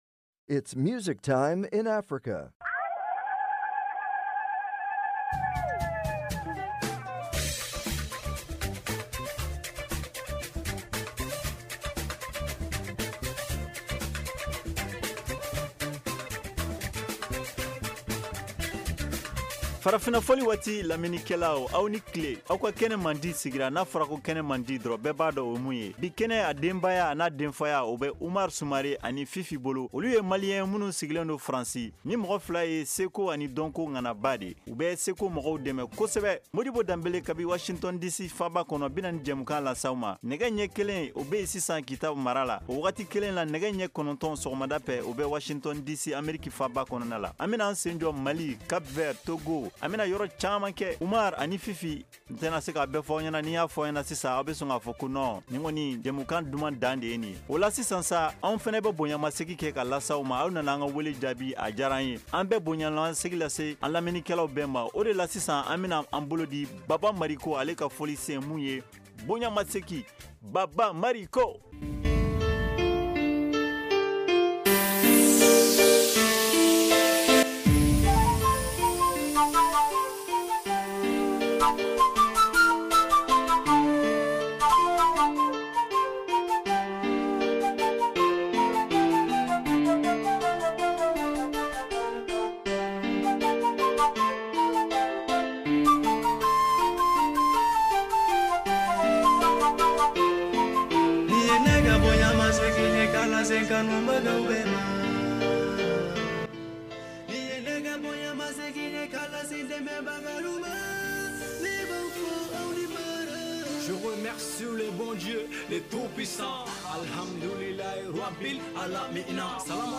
Farafina Fɔli Waati est une émission culturelle et musicale interactive en Bambara de la VOA.